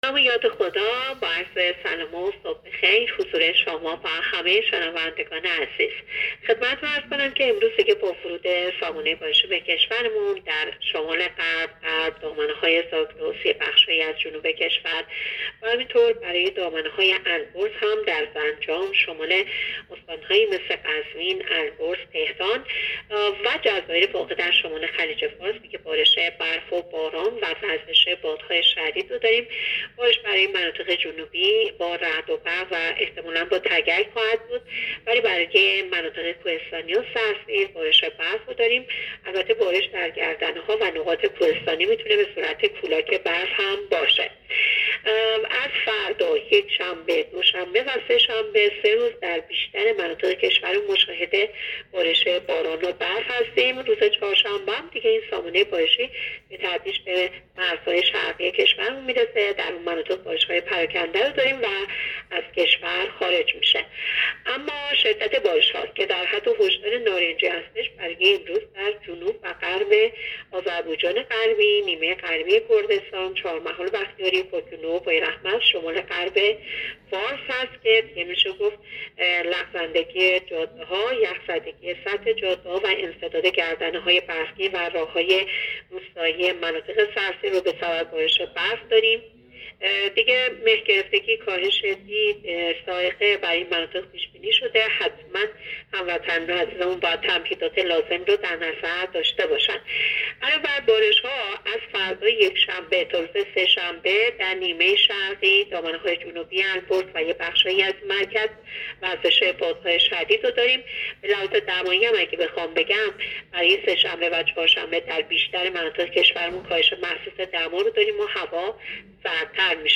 گزارش رادیو اینترنتی از آخرین وضعیت آب و هوای ششم دی؛